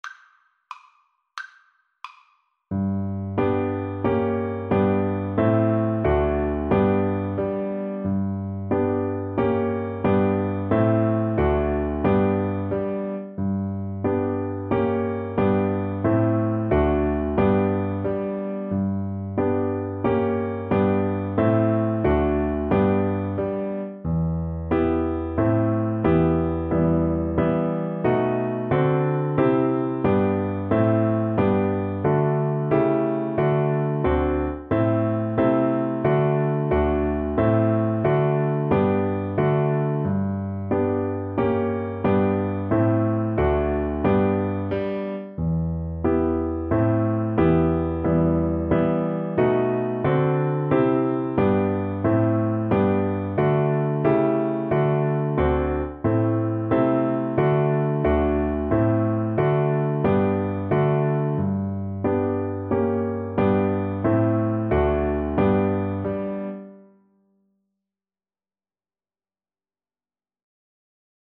Moderato . = 90
6/8 (View more 6/8 Music)
D4-G5
Traditional (View more Traditional Viola Music)